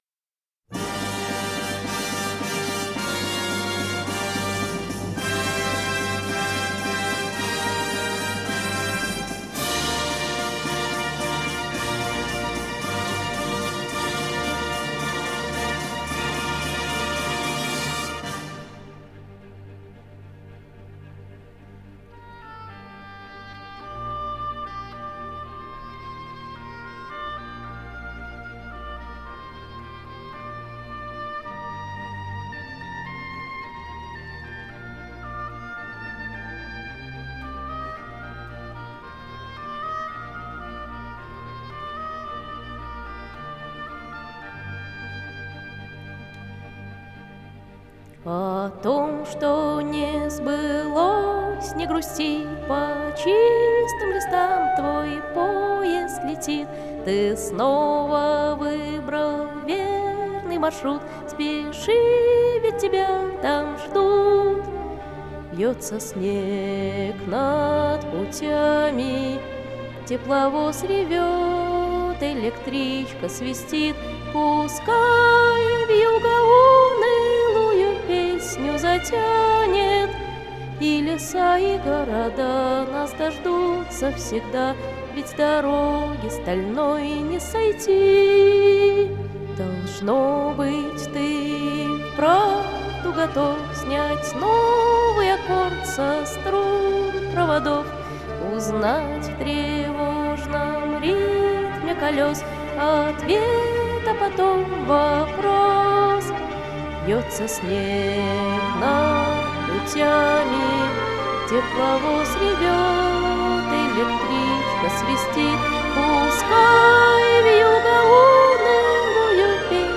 4. Russian audio ::